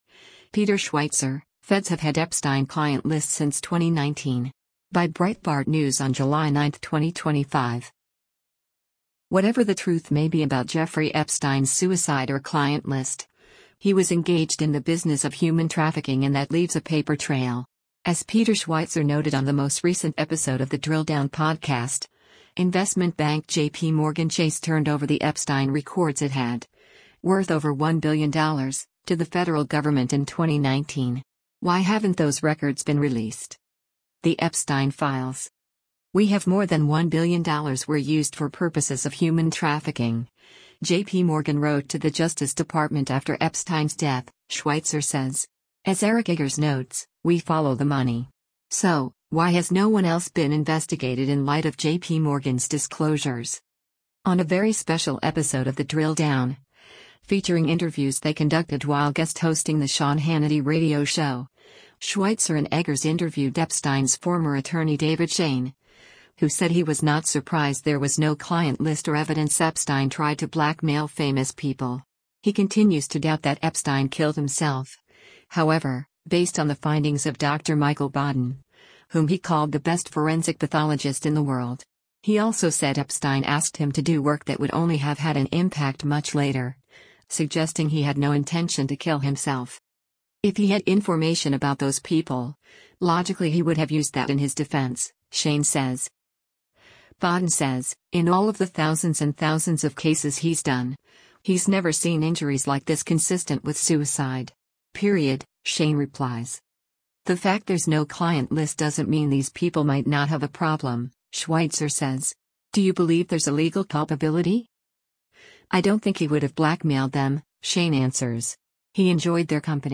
As part of their time on the Sean Hannity show, they also interviewed one of Congress’s newest members, Florida Republican Randy Fine, who fought to pass the president’s Big Beautiful Bill.